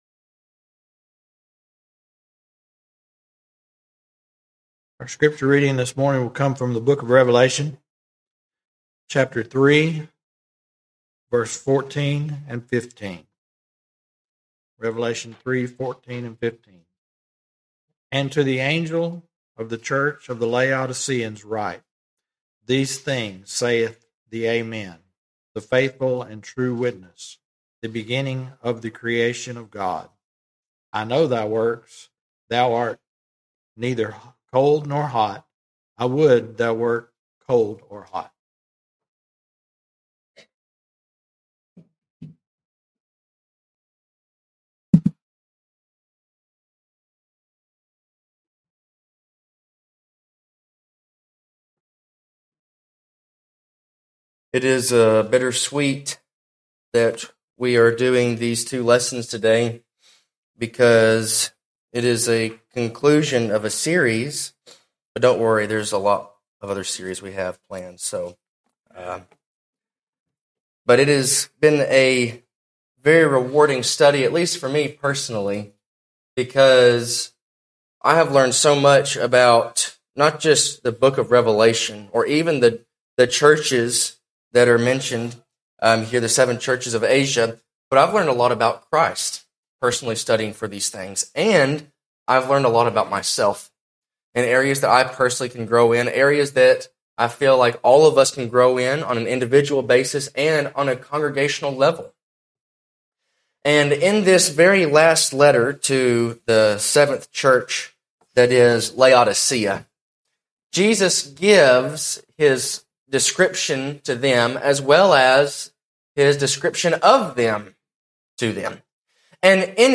Jesus the Infallible and Formidable King - Mabank church of Christ